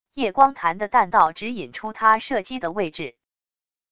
曳光弹的弹道指引出他射击的位置wav音频生成系统WAV Audio Player